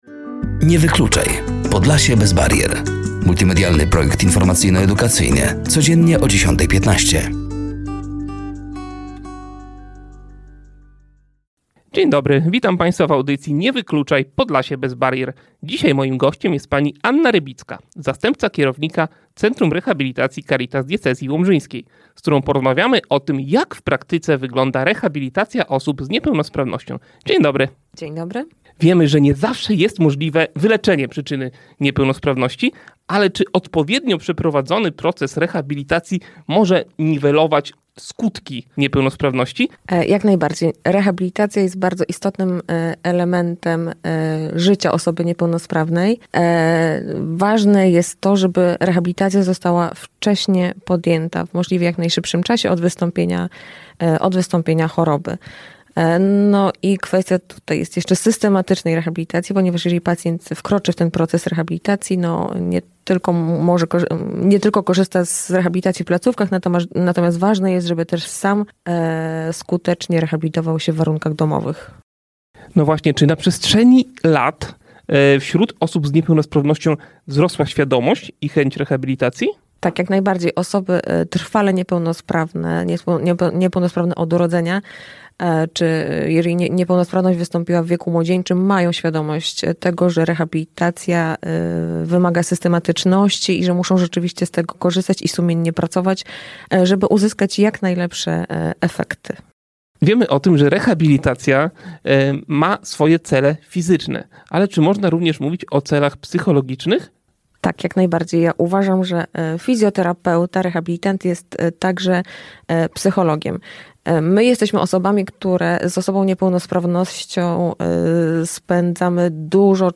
Jak w praktyce wygląda rehabilitacja osób z niepełnosprawnościami, jakie trudności systemowe napotyka i jak można ułatwić dostęp pacjenta do terapeuty – między innymi o tym rozmawialiśmy w drugiej audycji z cyklu „Nie wykluczaj. Podlasie bez barier”.